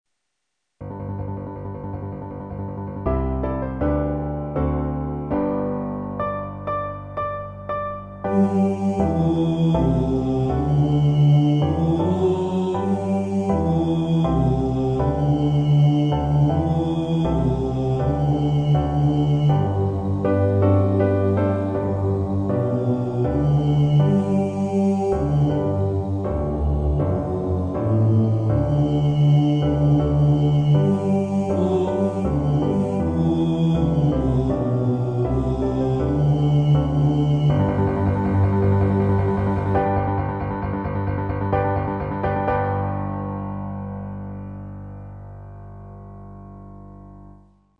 If you would like to practice up on a vocal part, here are some part recordings which may assist you.